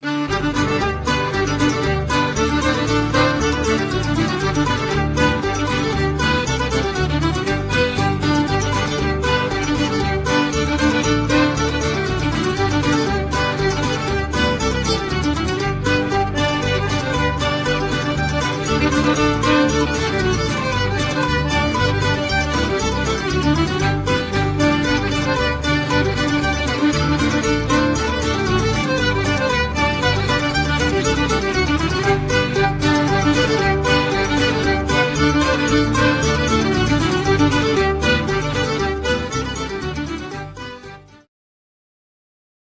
set dance